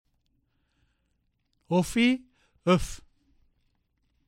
I.  Listen to the difference between o and ö.  This difference only shows up in the short forms.